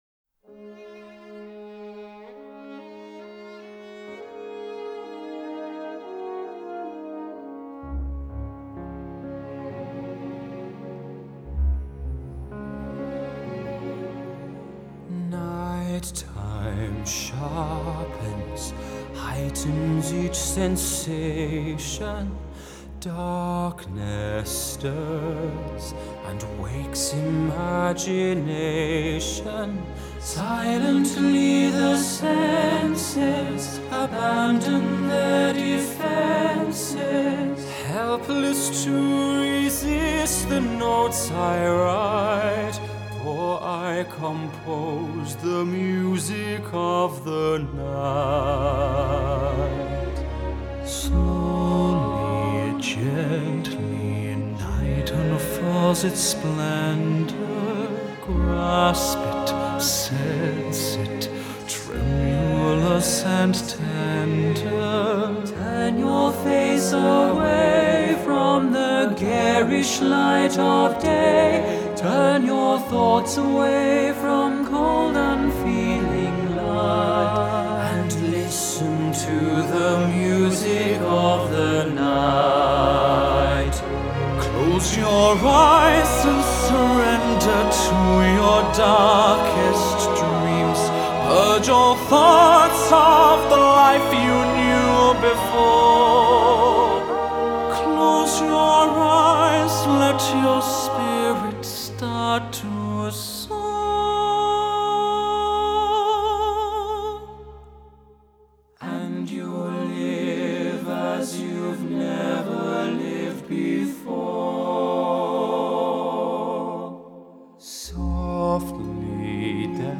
Genre: Vocal, Pop, Classical